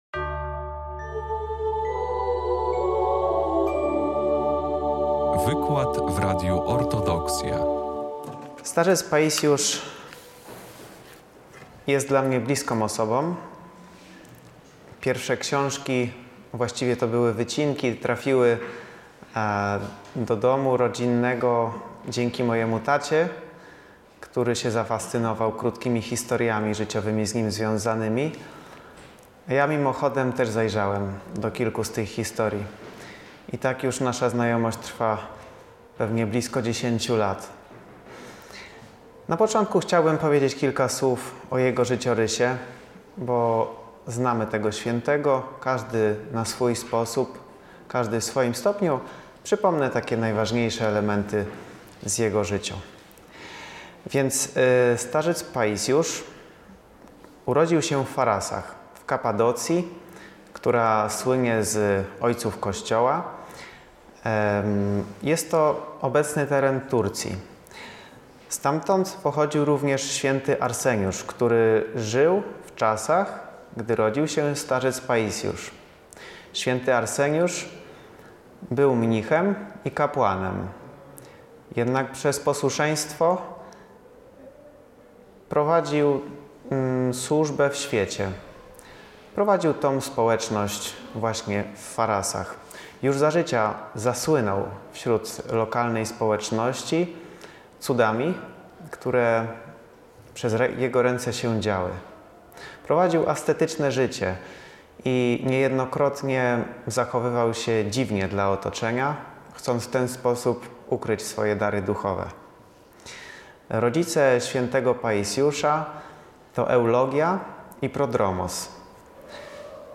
24 lutego 2025 roku roku w Centrum Kultury Prawosławnej w Białymstoku odbył się kolejny wykład w ramach Wszechnicy Kultury Prawosławnej.